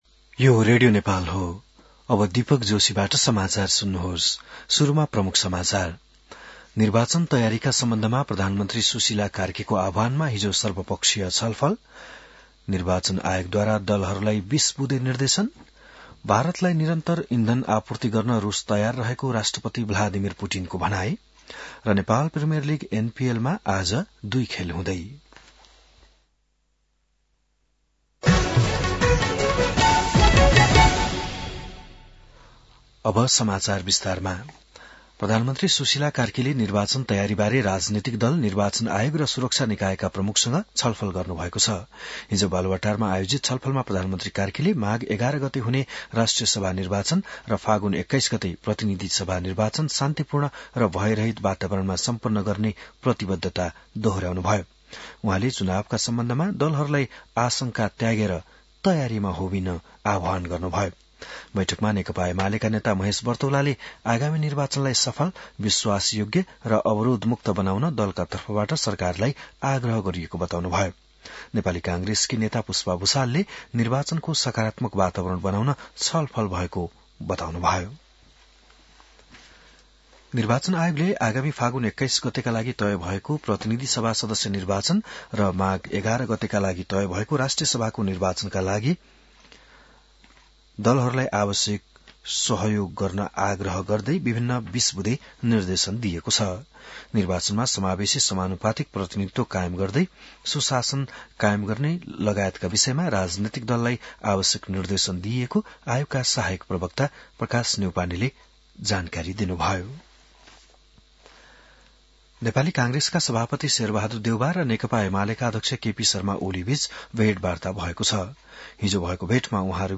बिहान ९ बजेको नेपाली समाचार : २० मंसिर , २०८२